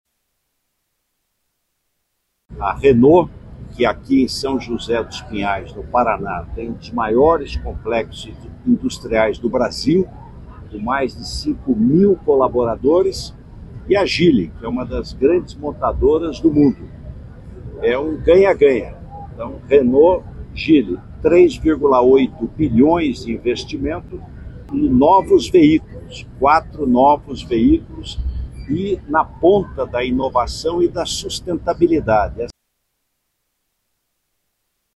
O vice-presidente da República e ministro do Desenvolvimento, Indústria, Comércio e Serviços, Geraldo Alckmin (PSB), destacou a parceria entre as duas montadoras e a definiu como um “ganha-ganha” na Região Metropolitana de Curitiba.